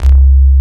RIPMOOG C2-R.wav